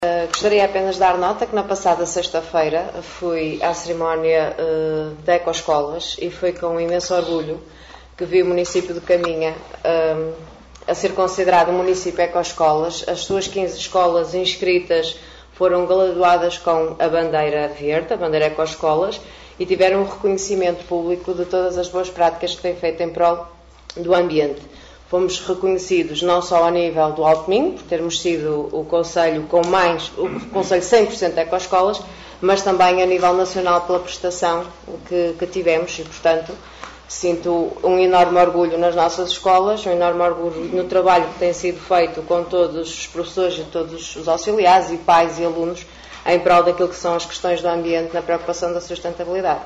Liliana Ribeiro, vereadora responsável pelo pelouro da educação a congratular-se pelo reconhecimento das escolas do concelho no que toda a boas práticas ambientais.